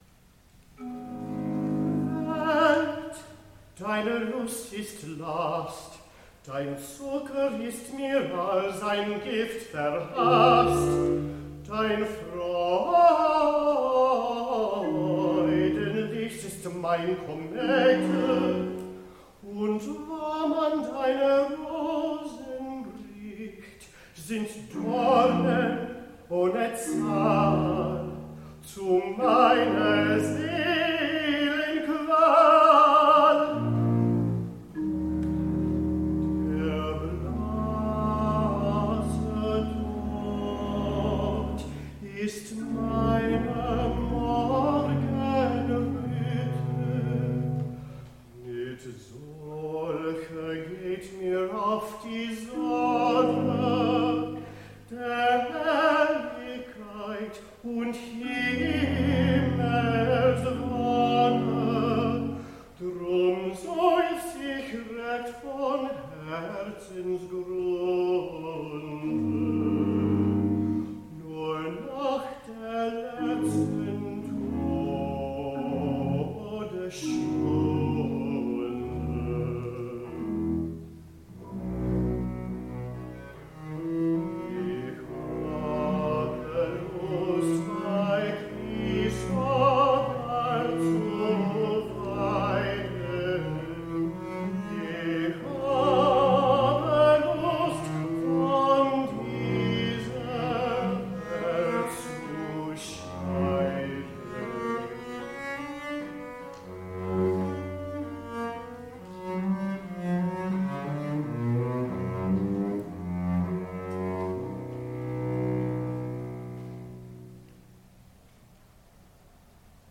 A Bach Vespers
The Blue Hill Bach Orchestra and Chorus
Sounds from this performance Concerto for Oboe & Strings in D minor, BWV 1059a Komm, Jesu, komm Komm, lass mich nicht langer warten Harpsichord Concerto in D Wir eilen De profundis Mein Verlangen BWV161 Wachet auf BWV140